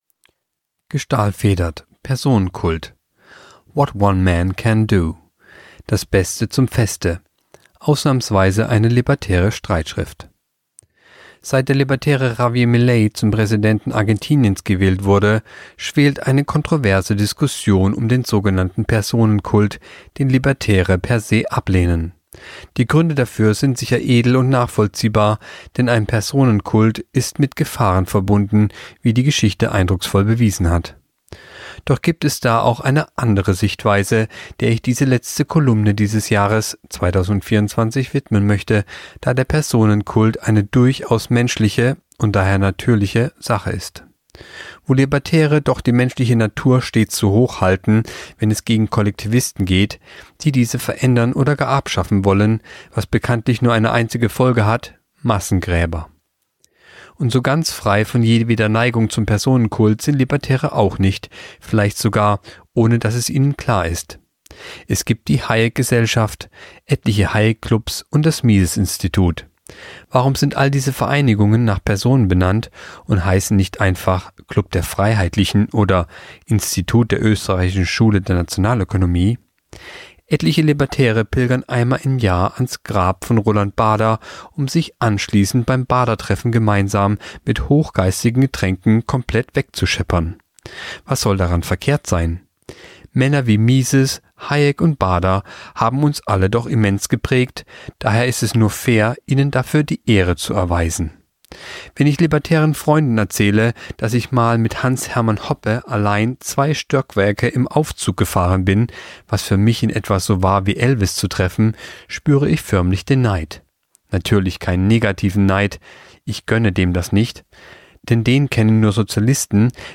Kolumne der Woche (Radio)What One Man Can Do